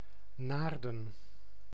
Naarden ([ˈnaːrdə(n)]
Nl-Naarden.ogg.mp3